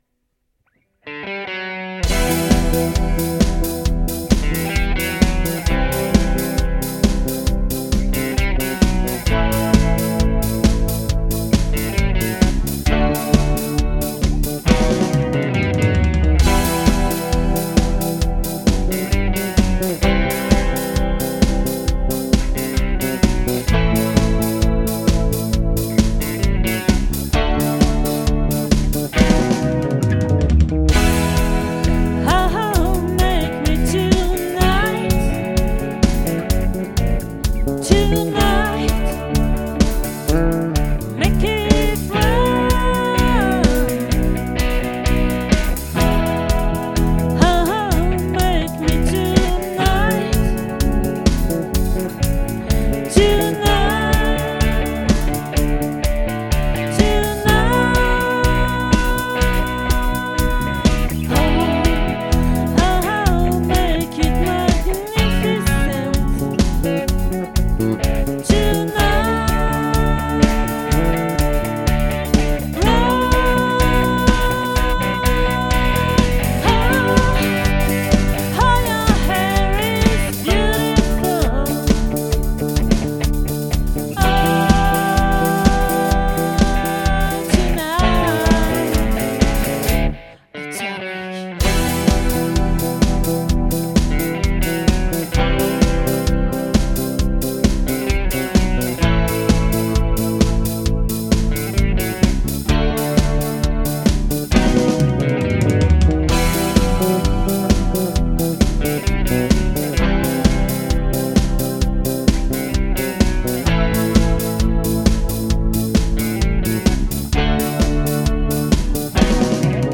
🏠 Accueil Repetitions Records_2022_11_09